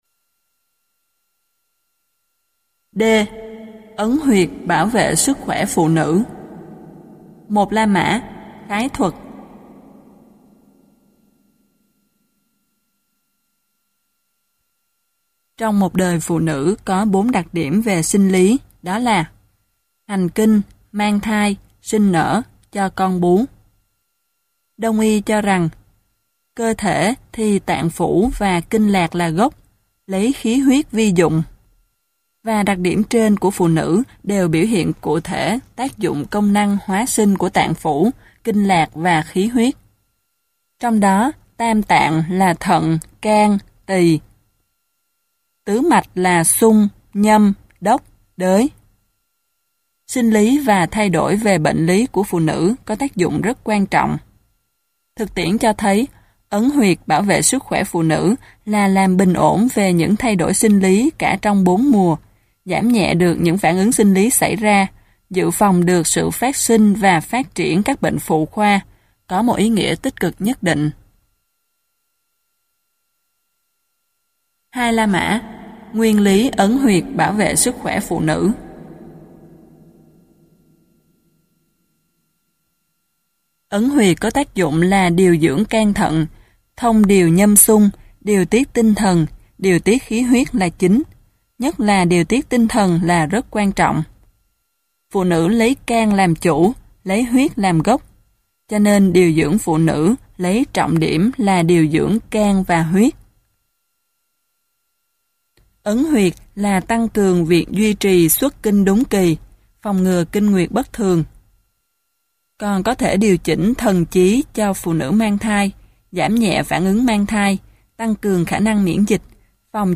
Sách nói Ấn Huyệt Bằng Tay Chữa Bệnh - Nguyễn Quang Thái - Sách Nói Online Hay
Ấn Huyệt Bằng Tay Chữa Bệnh Tác giả: Nguyễn Quang Thái Nhà xuất bản Hải Phòng ấn hành 2008 Giọng đọc: Nhiều người đọc